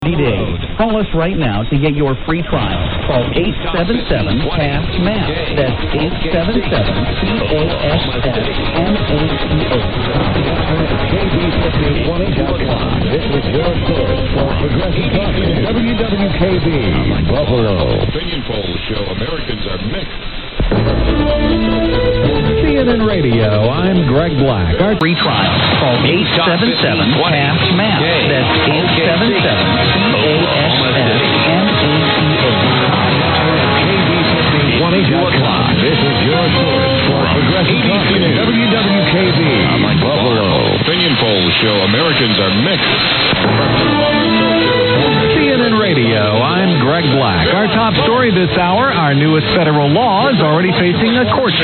GOOD SIGNALS AT 0600 TODAY:
Click below for a montage of stations at 0600 today, when there were some really nice signals around. Also a signal from AM then LSB of KOKC OK City, mixing with WWKB.
100325_0600_common_ids.mp3 (1,360k)